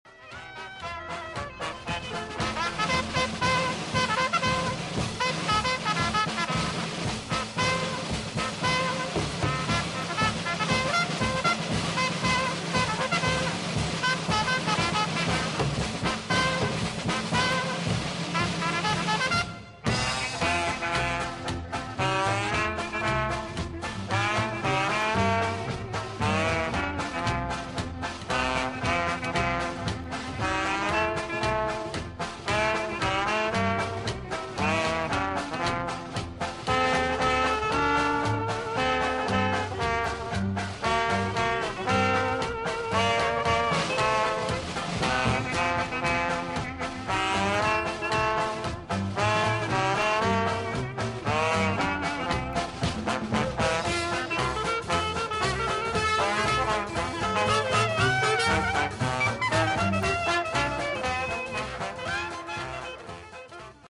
at the Deutschlandhalle